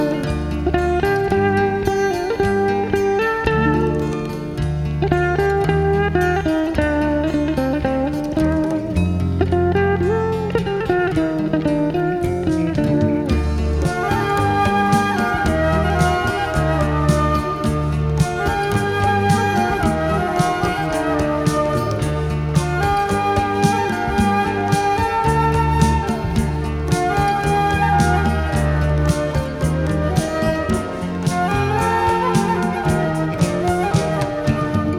Halk